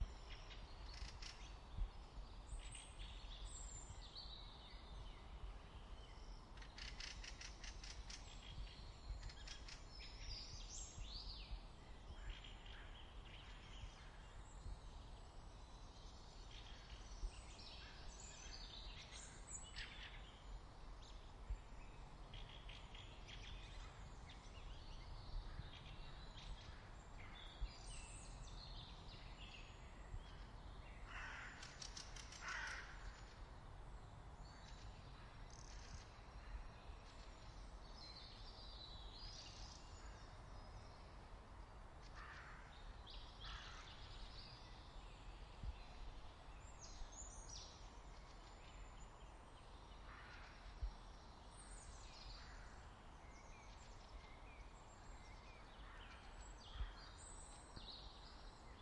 鸟类 " 在莫斯科附近的一个木制郊外村庄的各种鸟类
描述：各种各样的鸟在莫斯科附近的一个木郊区村庄，相当喧闹。